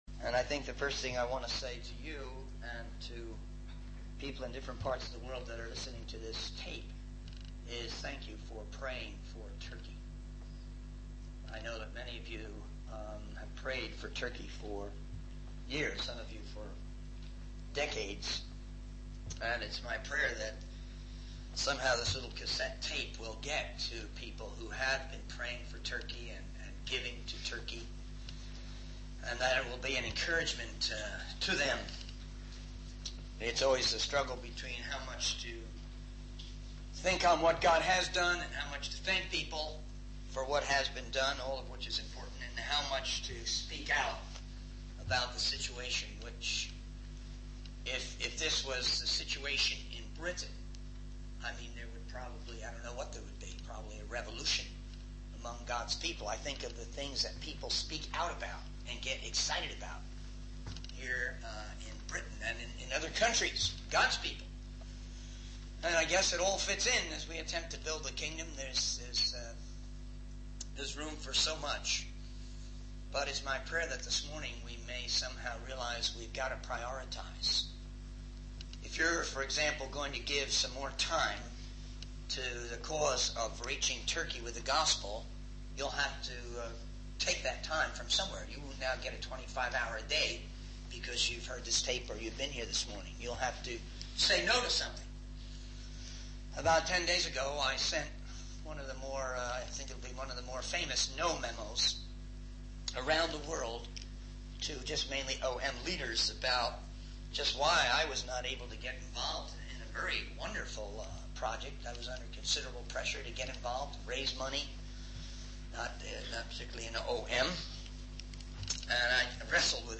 In this sermon, the speaker encourages listeners to push open doors and seek God's will in their lives. They emphasize the importance of using technology, such as email, to spread the word of God and share information about countries like Turkey.